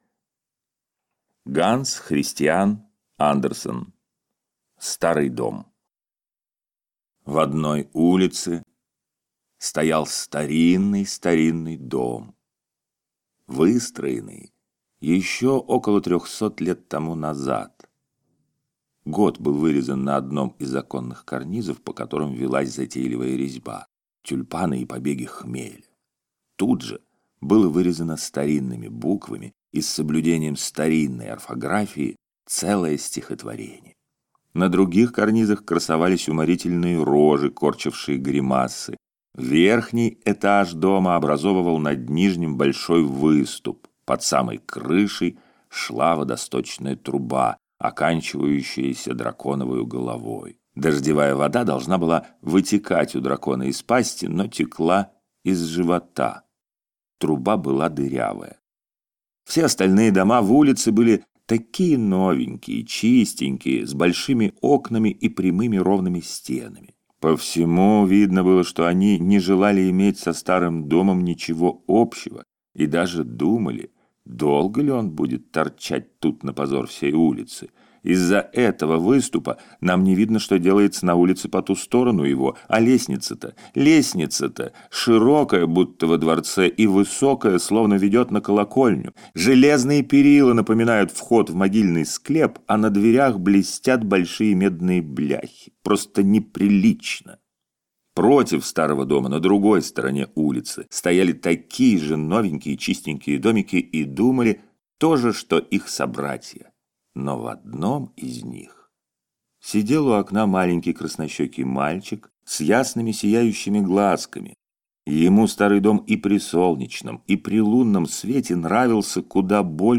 Аудиокнига Старый дом | Библиотека аудиокниг